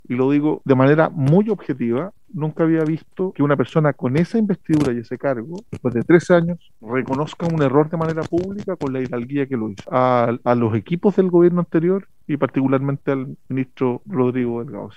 El presidente de la comisión, el socialista Raúl Leiva -quien agradeció la presencia de la ministra- también defendió la actitud que tuvo Siches.
cuna-izkia-leiva.mp3